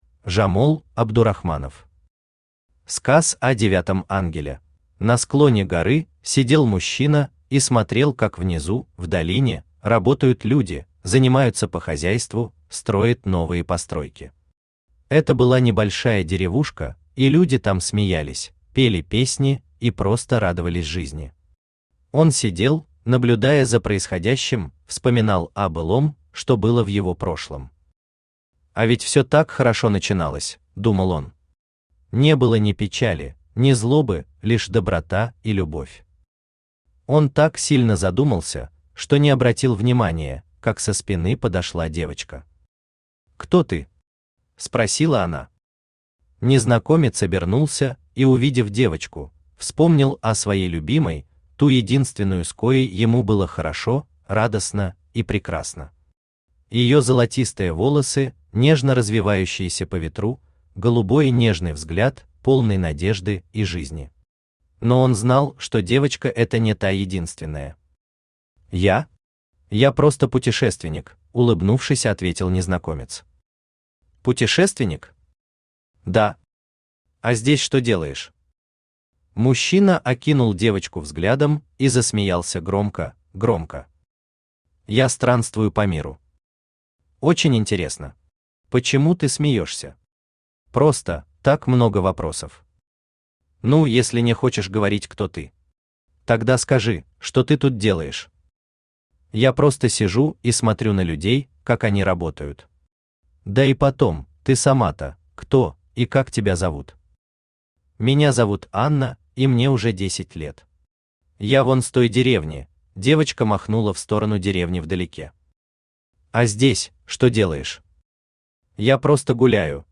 Аудиокнига Сказ о девятом ангеле | Библиотека аудиокниг
Aудиокнига Сказ о девятом ангеле Автор Жамол Мухаммадович Абдурахманов Читает аудиокнигу Авточтец ЛитРес.